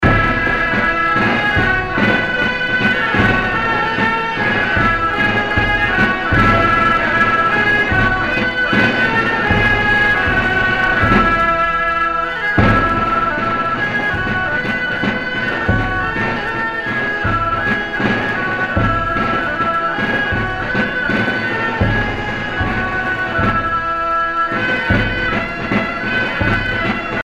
Fonction d'après l'analyste gestuel : à marcher